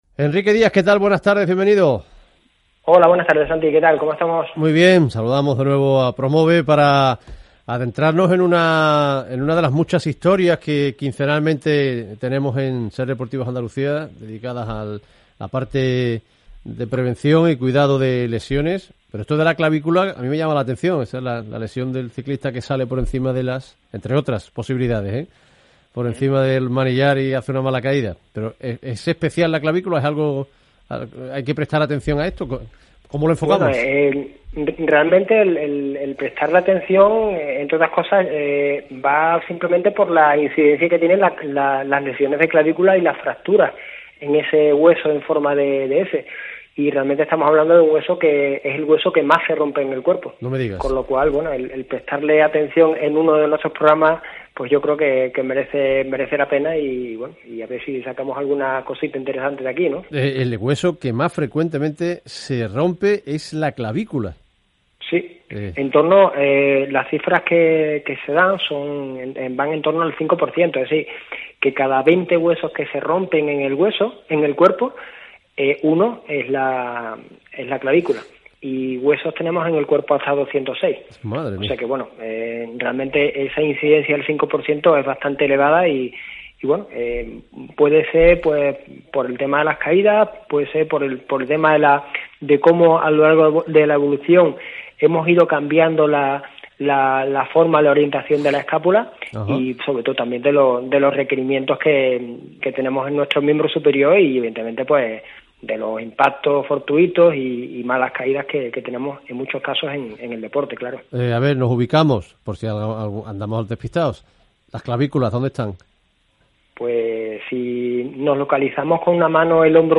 Nuevo programa de Radio en la CADENA SER.